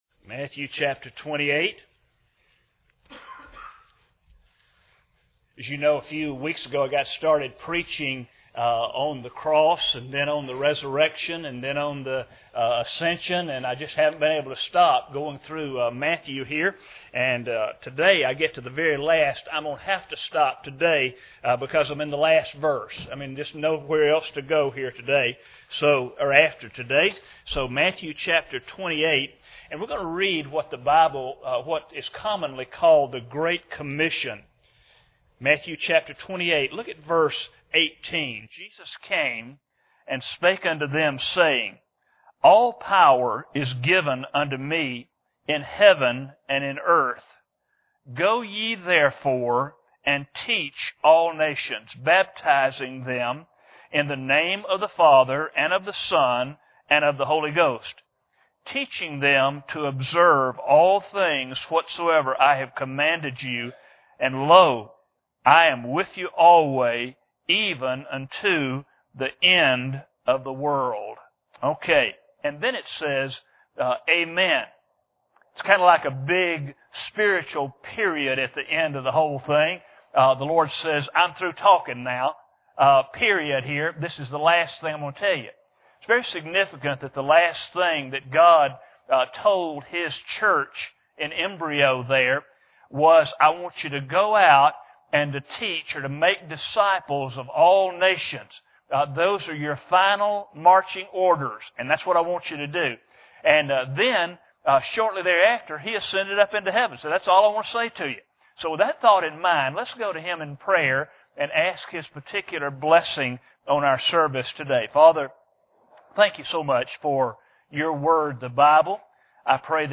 This sermon concludes the Easter story with the Great Commission of Jesus.